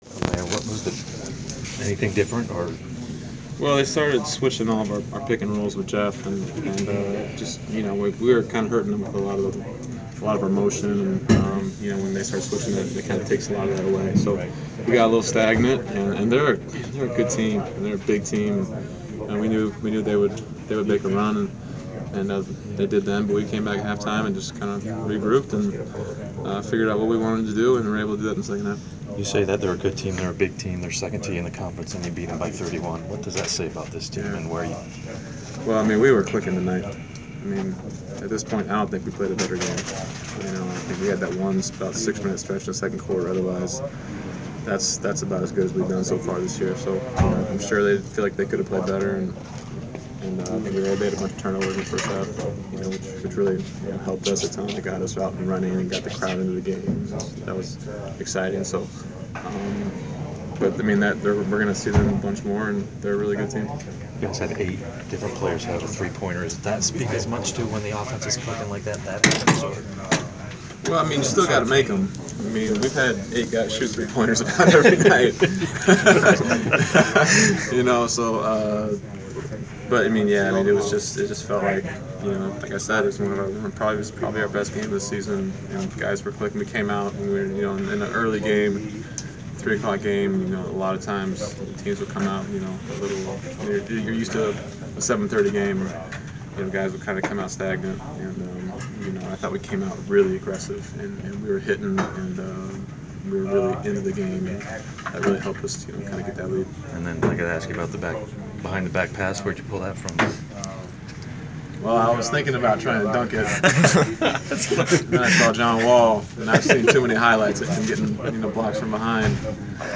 Inside the Inquirer: Postgame presser with Atlanta Hawks’ Kyle Korver (1/11/15)